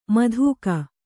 ♪ madhūka